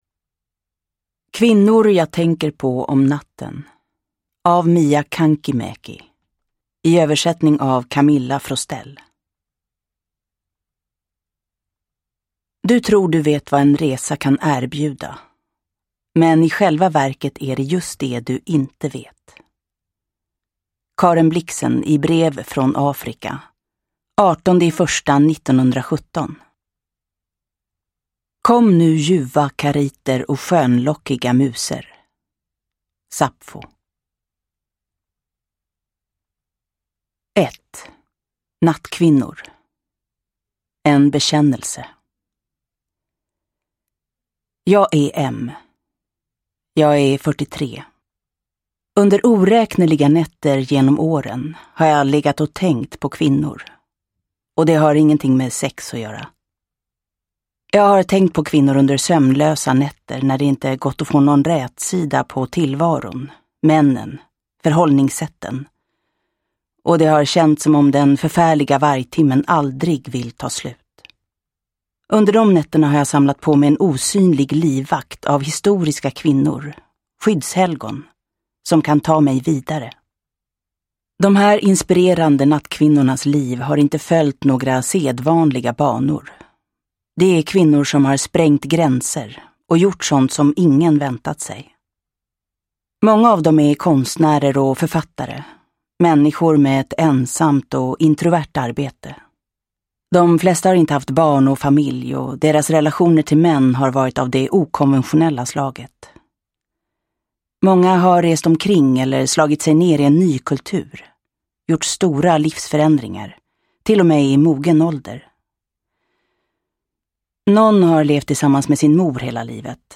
Kvinnor jag tänker på om natten – Ljudbok – Laddas ner
Uppläsare: Jessica Liedberg